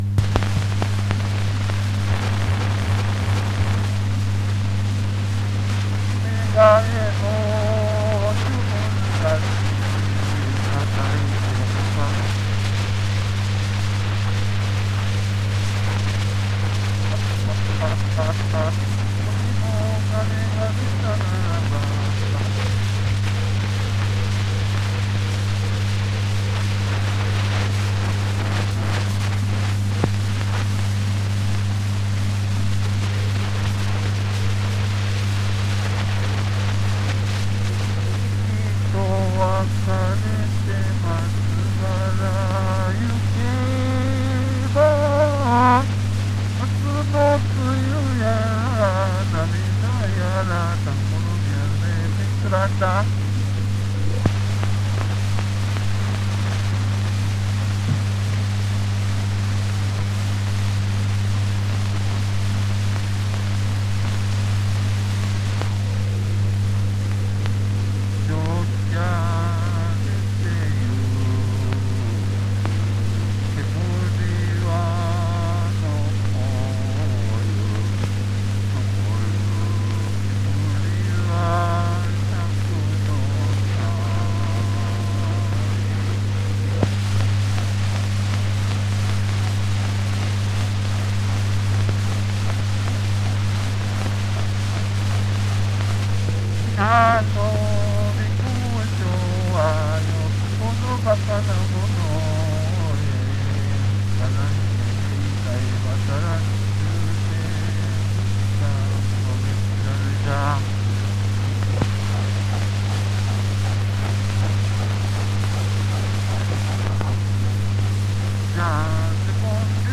・旧ベルリン大学で録音された蝋管による20世紀初頭の歌
Graf Dr. R. Goto (多分後藤新平のこと)が歌う歌